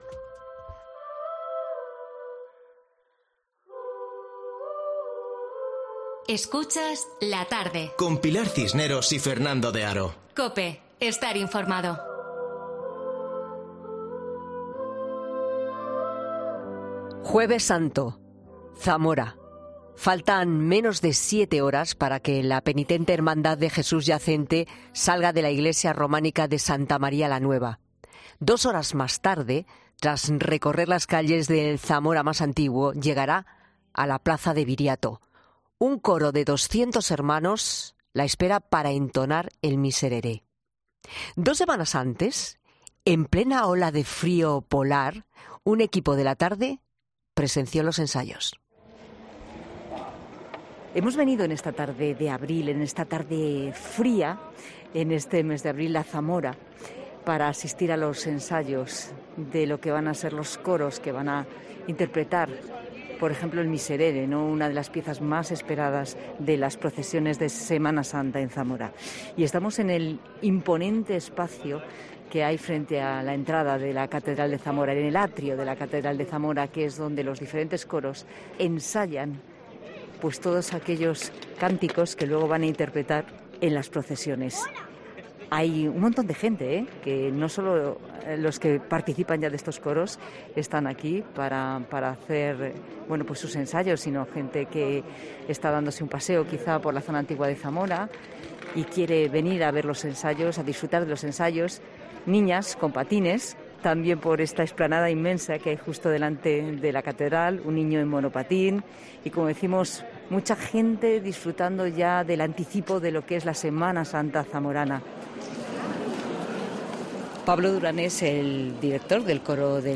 'La Tarde' acompaña a Penitente Hermandad de Jesús Yacente ante una noche donde la oscuridad y el silencio dan paso a la reflexión